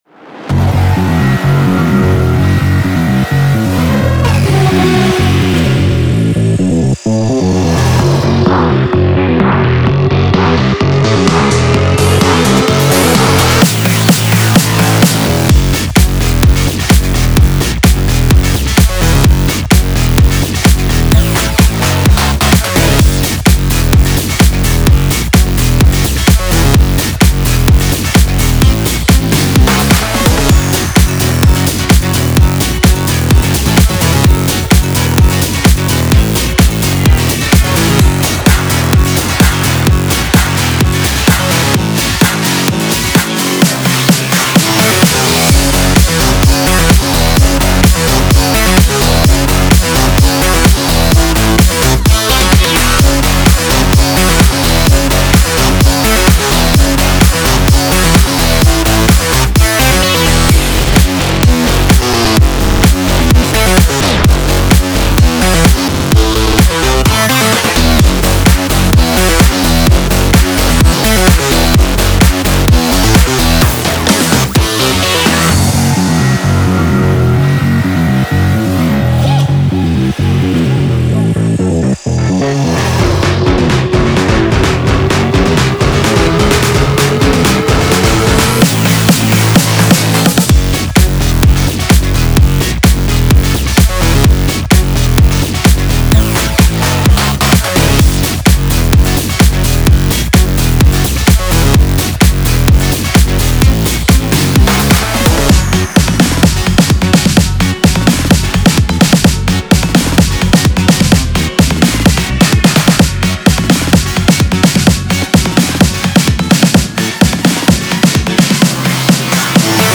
BPM128-128
Audio QualityPerfect (High Quality)
Bass House song for StepMania, ITGmania, Project Outfox